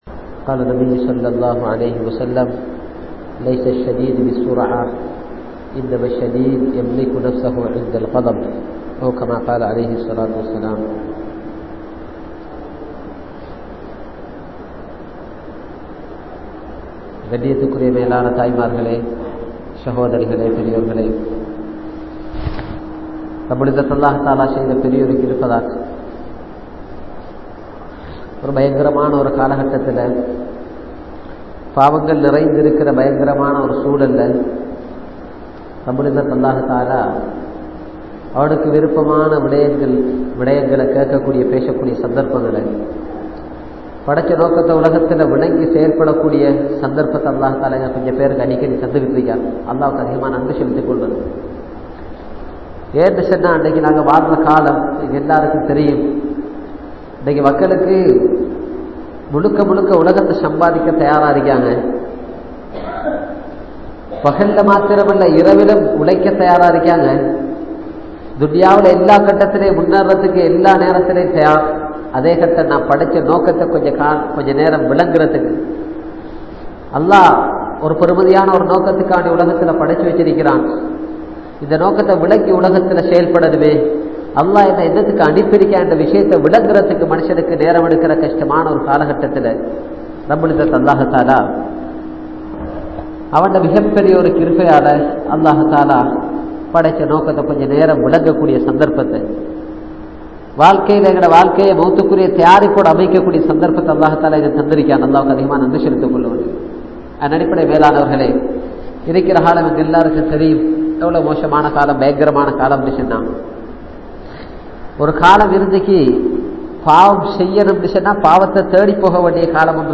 Manniungal Maranthidungal (மண்ணியுங்கள் மறந்திடுங்கள்) | Audio Bayans | All Ceylon Muslim Youth Community | Addalaichenai